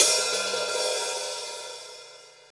paiste hi hat3 open.wav